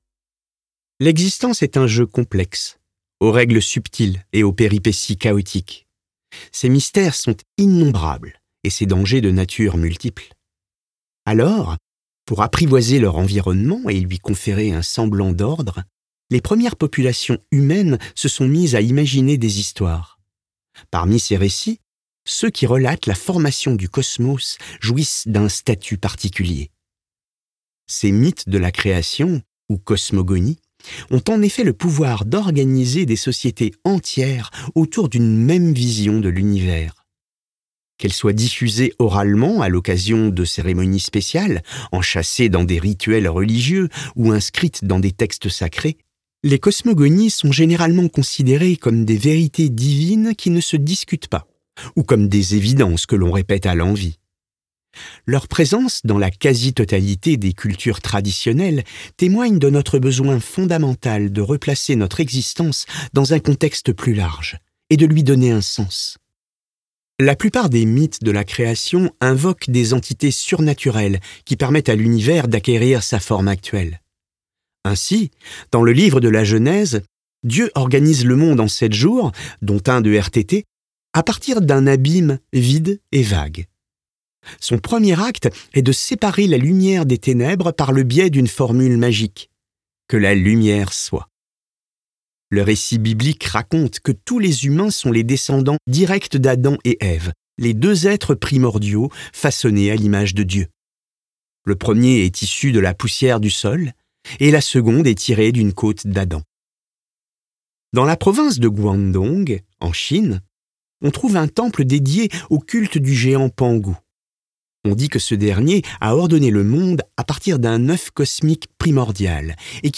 LIVRE AUDIO - Le Cosmos et nous (Sébastien Carassou)
32 - 64 ans - Baryton